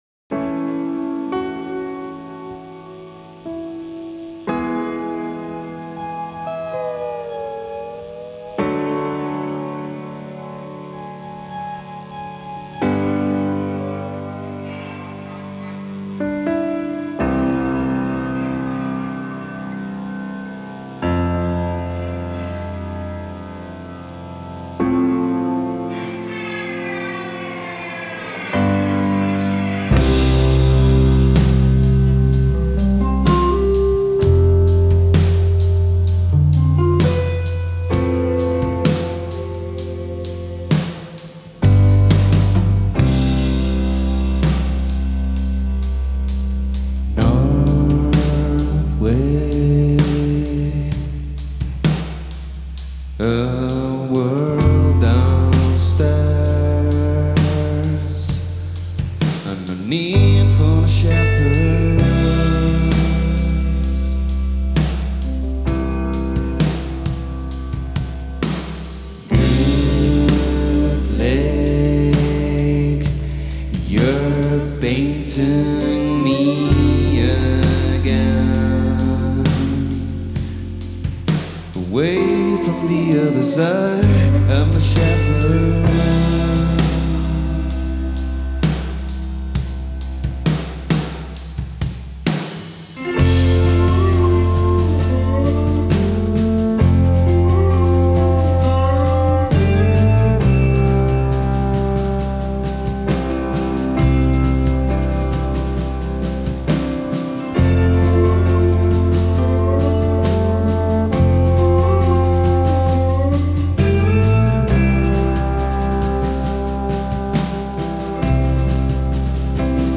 Canary Island Indie